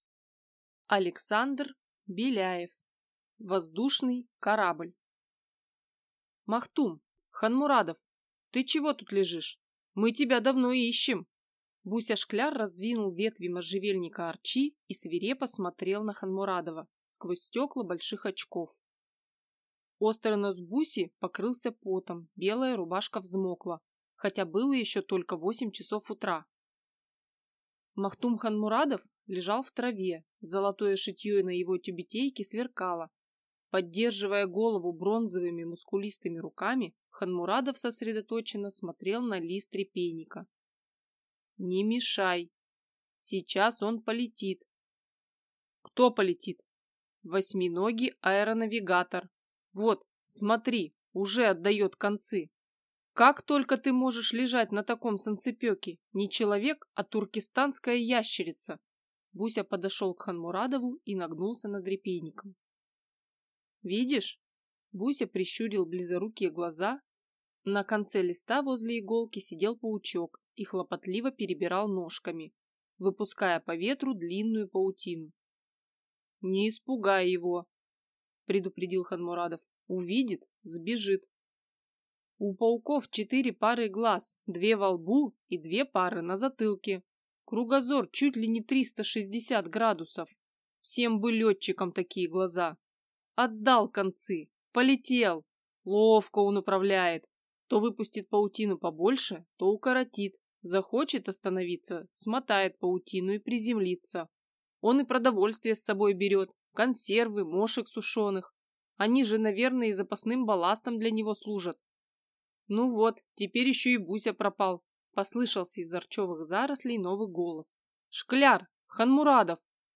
Аудиокнига Воздушный корабль | Библиотека аудиокниг